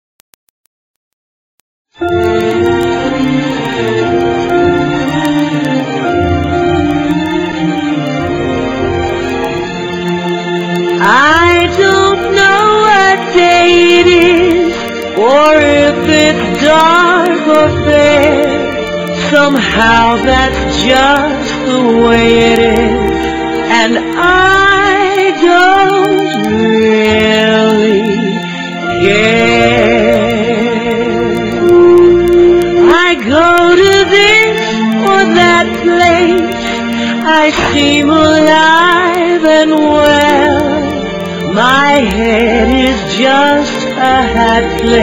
NOTE: Vocal Tracks 1 Thru 11